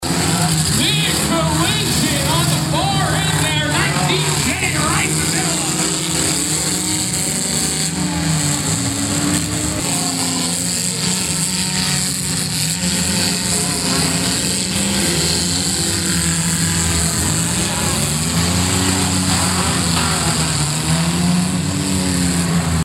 The fair went out with a bang, and a smash and a crash Saturday night.
big-collision.mp3